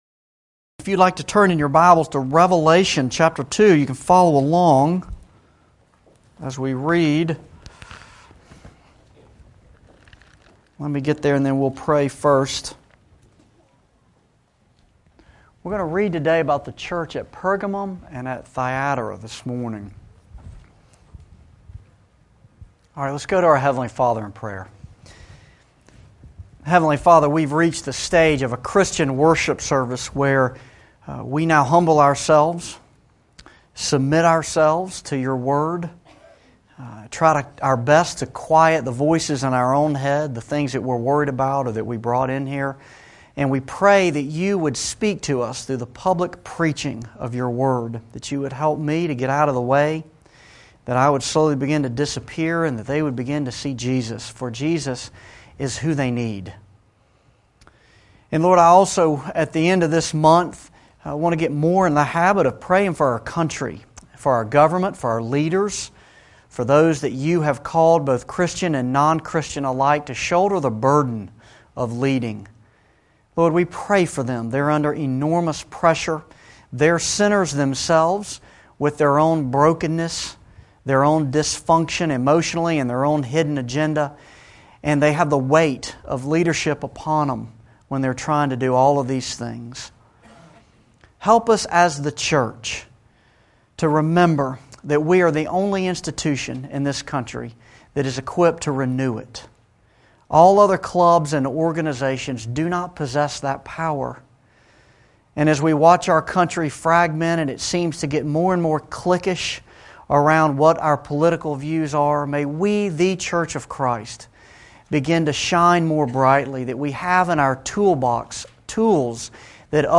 Revelation-Sermon-8.mp3